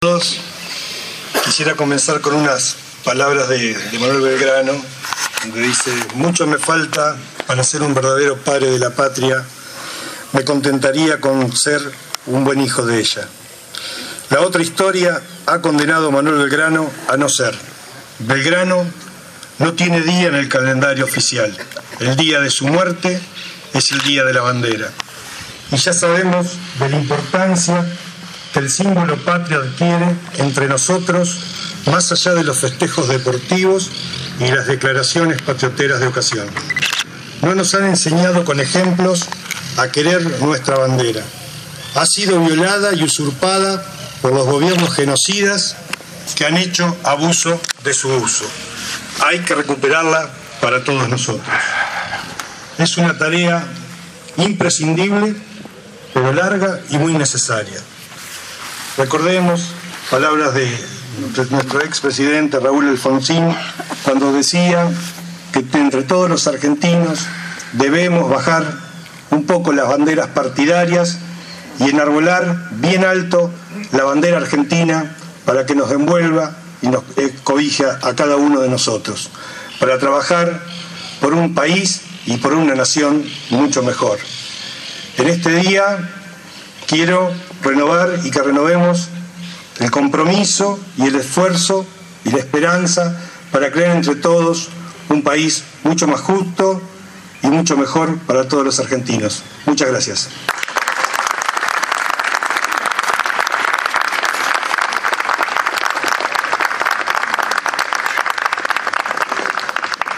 El Intendente Municipal, Víctor Aiola, durante el acto por el Día de la Bandera en Rawson.
Esta mañana, frente al mástil ubicado en plaza General San Martín, se llevó a cabo el acto oficial del Partido de Chacabuco, por el Día de la Bandera.
Aiola-Acto-Día-de-la-Bandera.mp3